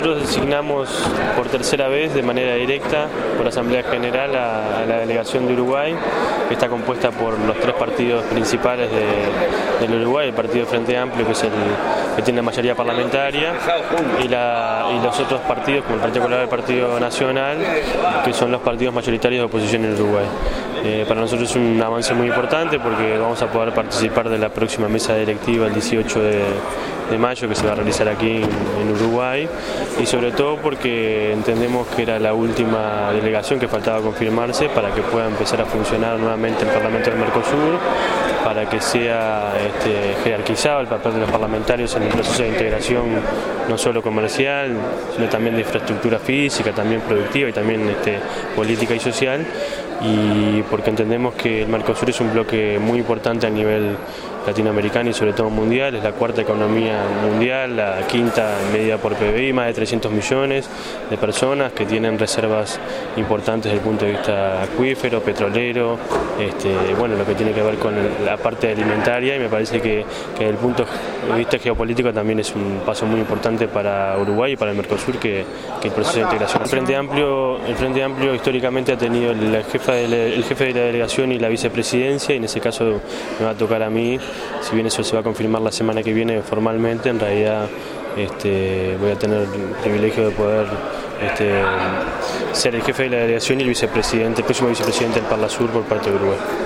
Parlamentario Daniel Caggiani